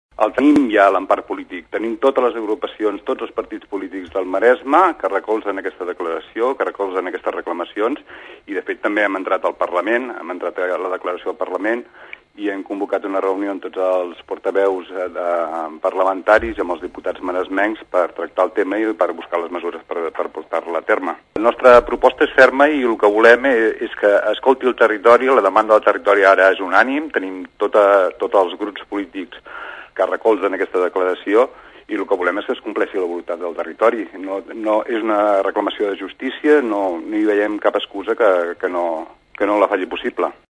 L’escolten en declaracions a la Xarxa.